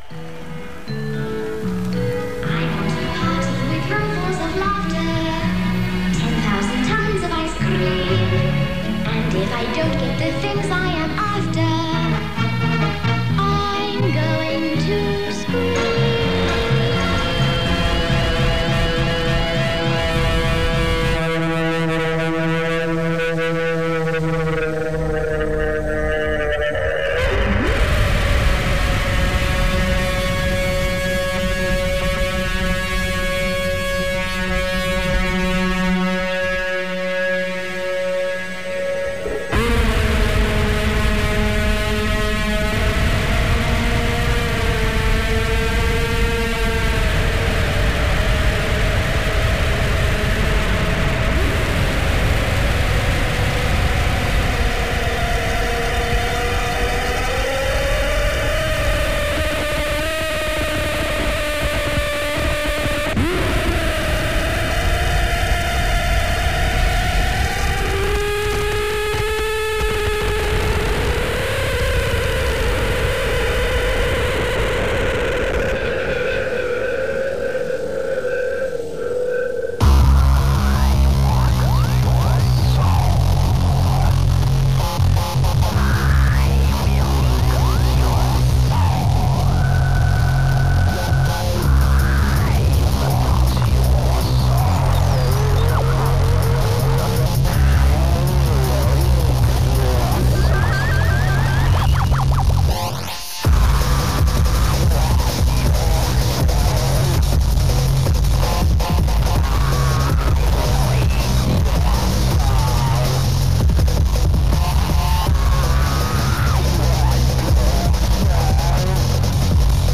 Liveset 1.